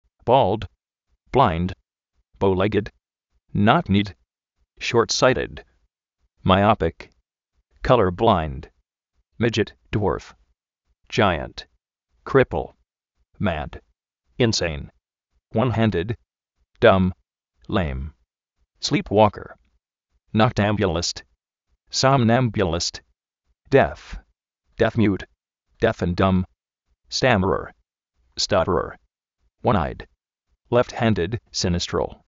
Vocabulario en ingles, diccionarios de ingles sonoros, con sonido, parlantes, curso de ingles gratis
bóld
bláind
bóu-légid
nók-ní:d